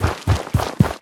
biter-walk-big-3.ogg